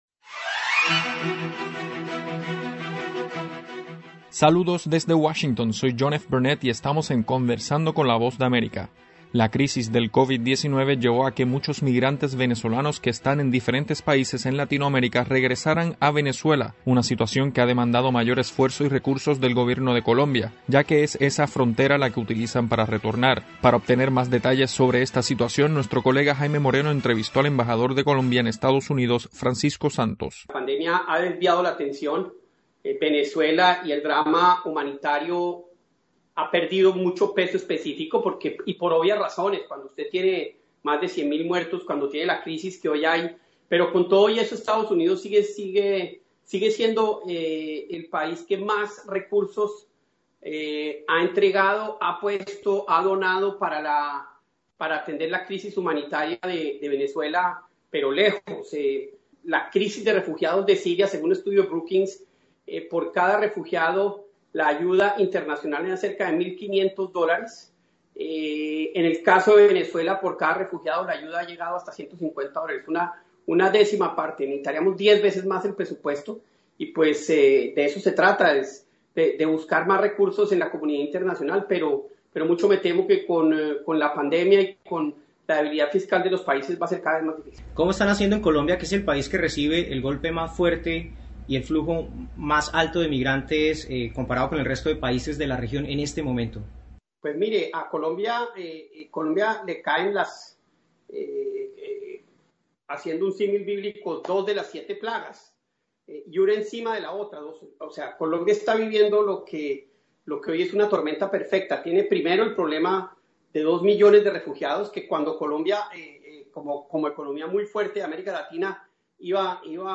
Conversamos con Francisco Santos, embajador de Colombia en Estados Unidos, hablando sobre la crítica situación que enfrenta el gobierno de Colombia con miles de migrantes venezolanos que buscan retornar a su país cruzando la frontera enfrentando la pandemia del COVID-19 además de su propia crisis humanitaria.